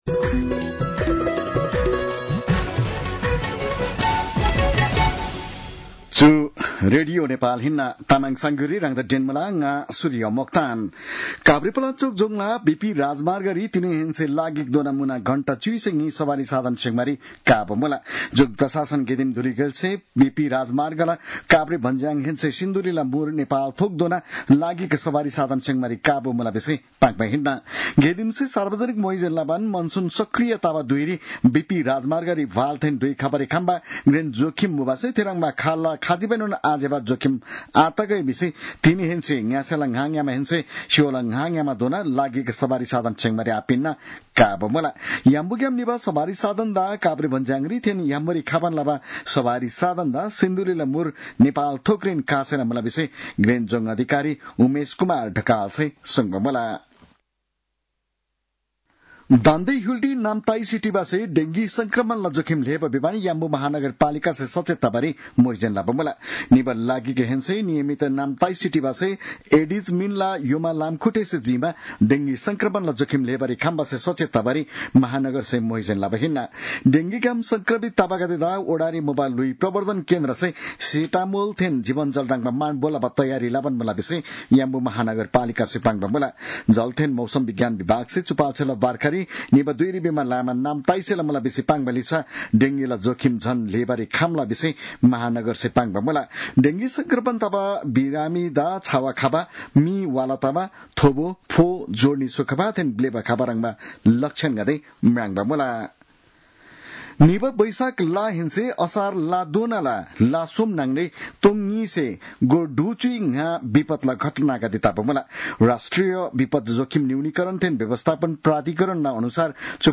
तामाङ भाषाको समाचार : १ साउन , २०८२